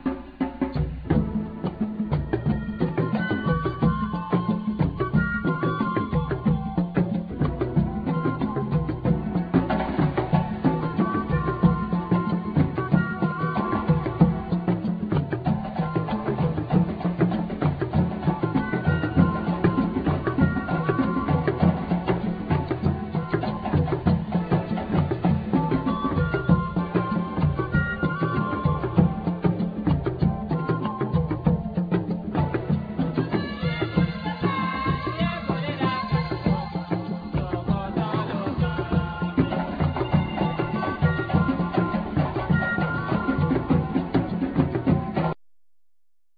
Trumpet,Keyboards
Percussion
Balafon
Djembe
Flute,Percussion,Vocal
Tama Soucou(Talking Drums)
Bara drum